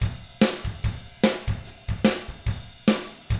146ROCK T2-R.wav